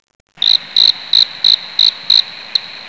Cricket
CRICKET.wav